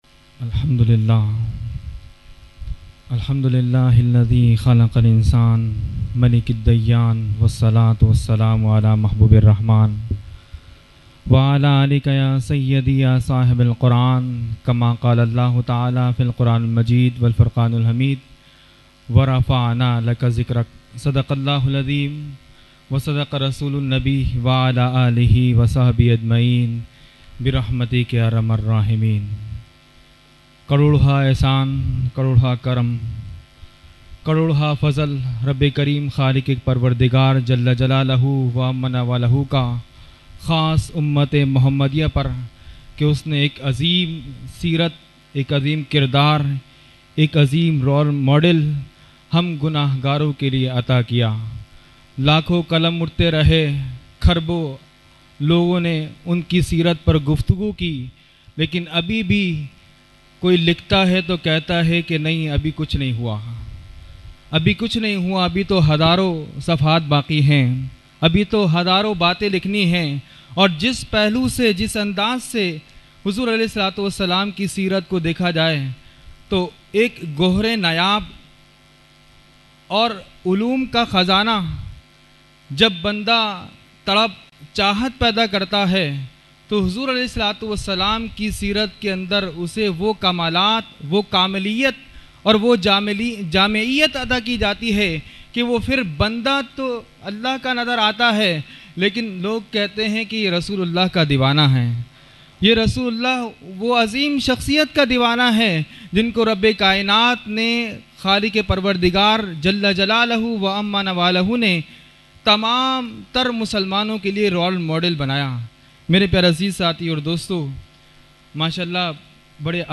Mehfil e Jashne Subhe Baharan held on 28 September 2023 at Dargah Alia Ashrafia Ashrafabad Firdous Colony Gulbahar Karachi.
Category : Speech | Language : UrduEvent : Jashne Subah Baharan 2023